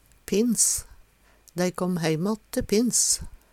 pinns - Numedalsmål (en-US)